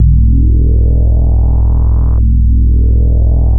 JUP 8 E2 9.wav